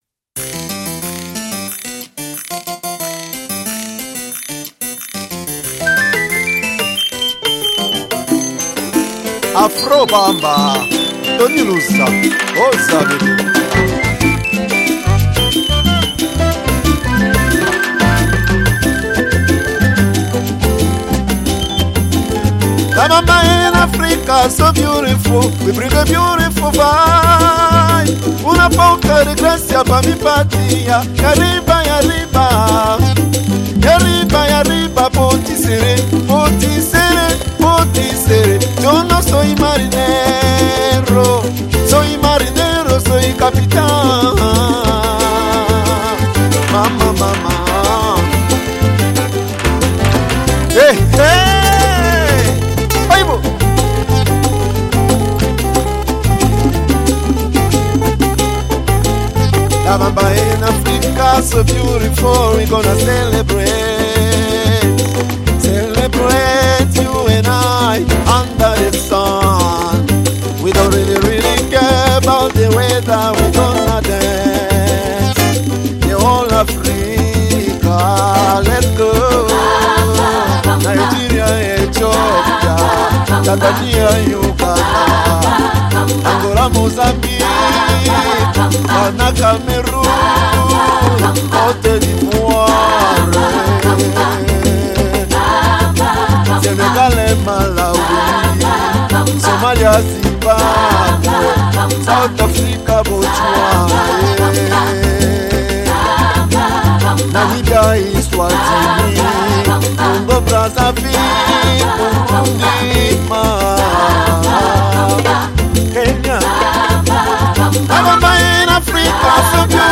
Afro heater